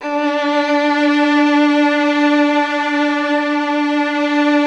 MELLOTRON .4.wav